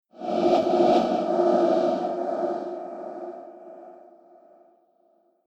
Âm thanh Vang Vọng bóng tối kinh dị
Crowd sound effect Âm thanh Dội, Vang Vọng ở đường hầm tối
Thể loại: Âm thanh hung dữ ghê sợ
Description: Tiếng động gợi cảm giác ghê rợn, ám ảnh, bí ẩn như bước chân vang lên trong hành lang trống rỗng, tiếng gió hú xuyên qua khe cửa, hay tiếng vọng xa xăm từ nơi không người.
am-thanh-vang-vong-bong-toi-kinh-di-www_tiengdong_com.mp3